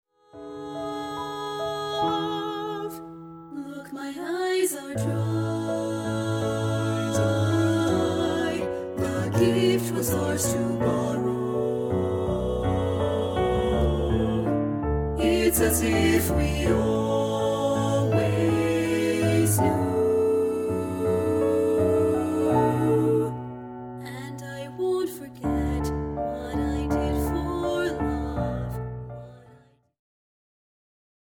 • Full Mix Track
• Accompaniment Track (if applicable)